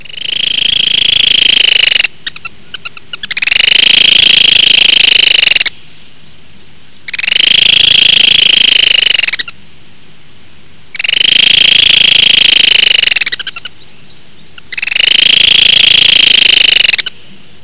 Completo (694 Kb) De advertencia
colilarga_advertencia.wav